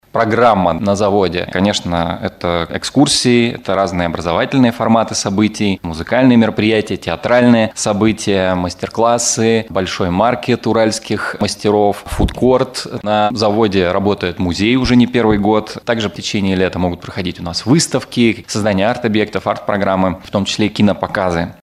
на пресс-конференции «ТАСС-Урал»